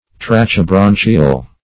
Search Result for " tracheobronchial" : The Collaborative International Dictionary of English v.0.48: Tracheobronchial \Tra`che*o*bron"chi*al\, a. (Zool.)
tracheobronchial.mp3